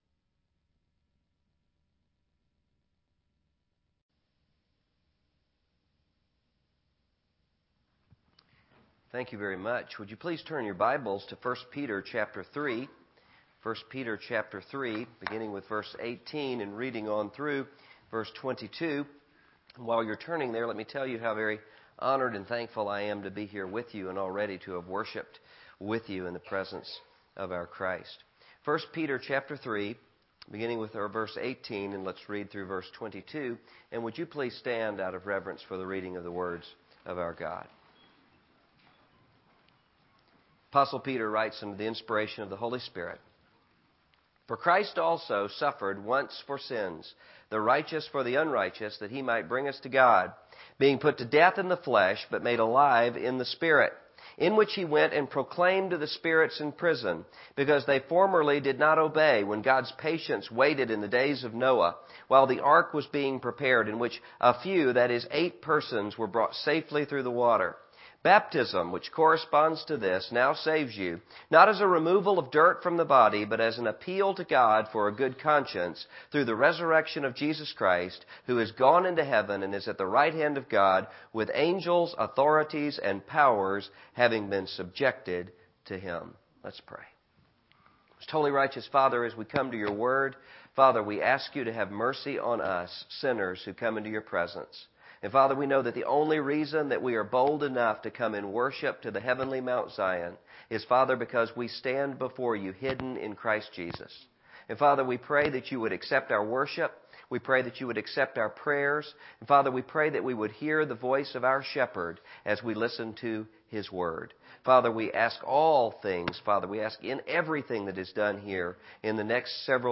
In this sermon, the pastor shares a personal anecdote about a friend who became paranoid about who was listening to his phone conversations. The pastor emphasizes the importance of not fleeing from suffering and encourages listeners to arm themselves with the same mindset as Christ, who suffered in the flesh. He urges American Christians not to seek out celebrities or catchy slogans, but to focus on the will of God.